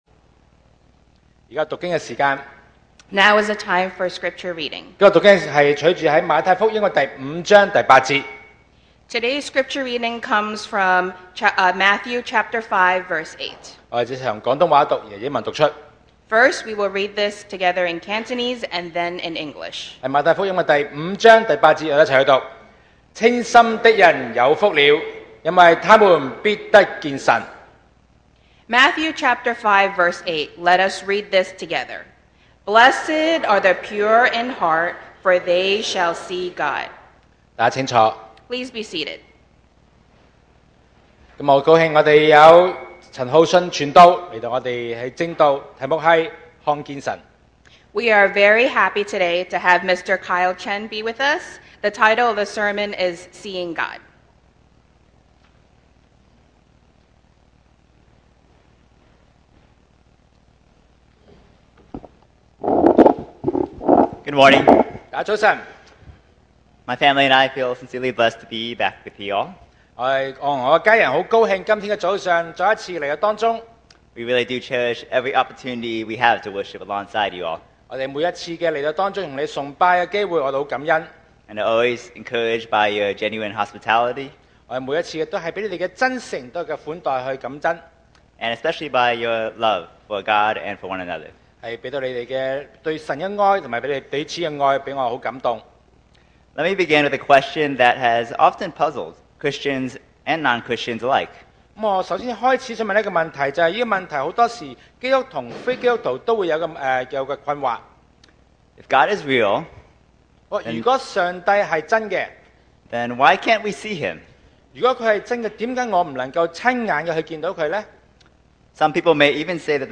2025 sermon audios 2025年講道重溫
Service Type: Sunday Morning